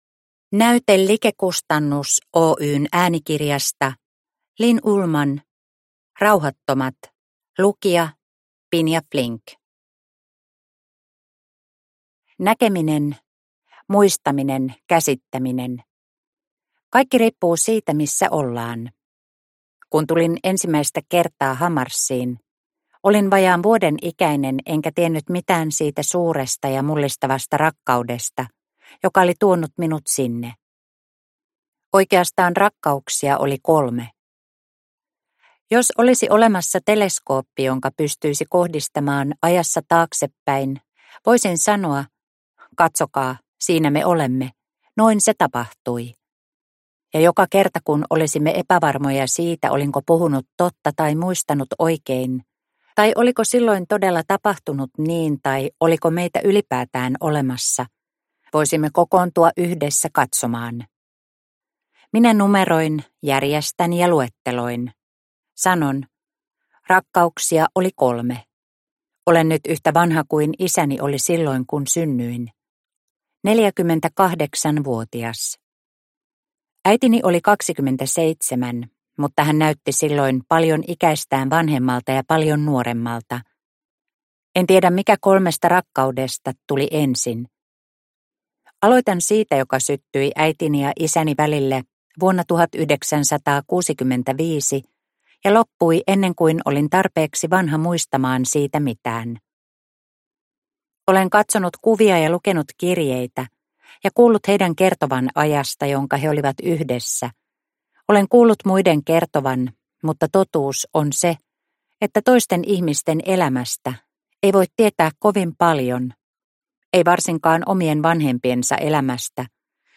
Rauhattomat – Ljudbok – Laddas ner